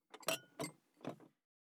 214,トン,ゴト,ポン,ガシャン,ドスン,ストン,カチ,タン,バタン,スッ
コップ効果音物を置く
コップ